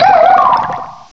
sovereignx/sound/direct_sound_samples/cries/frillish.aif at 5119ee2d39083b2bf767d521ae257cb84fd43d0e
frillish.aif